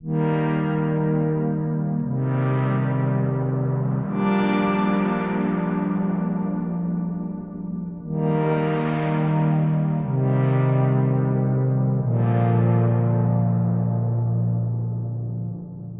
opening synth